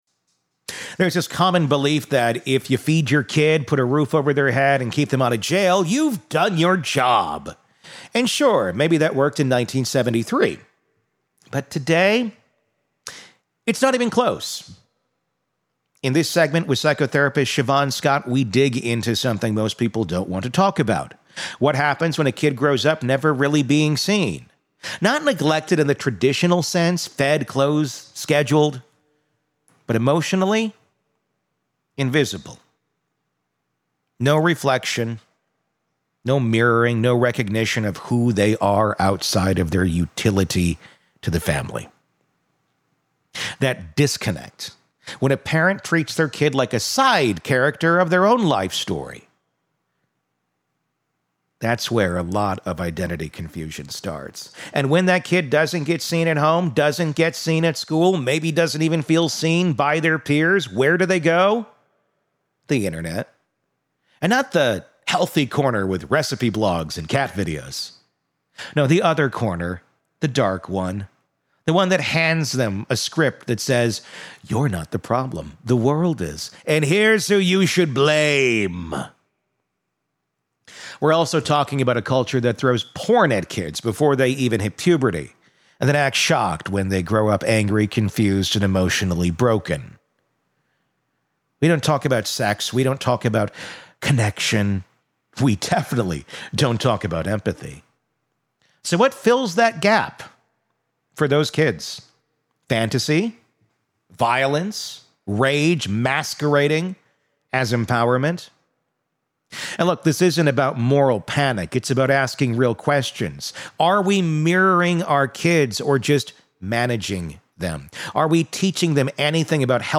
True Crime Today | Daily True Crime News & Interviews / Can An Incel’s Dark Thinking Change or Be Fixed?